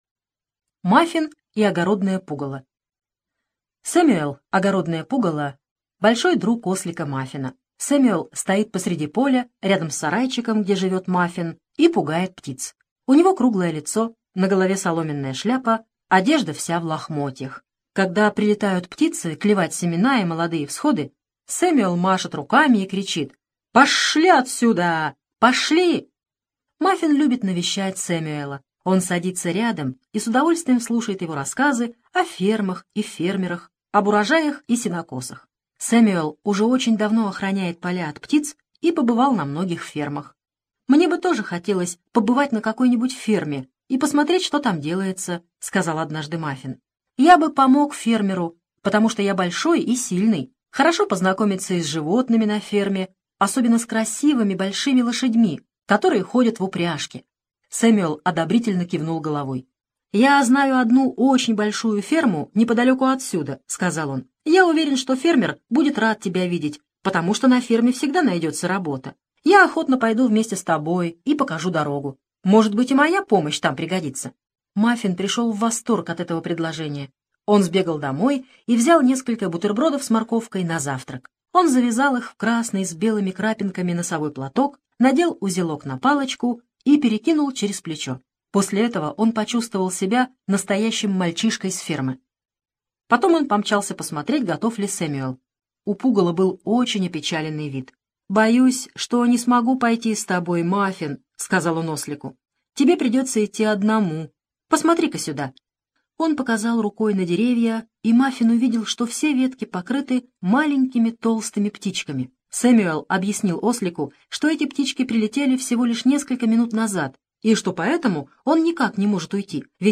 Мафин и огородное пугало - аудиосказка Хогарт - слушать онлайн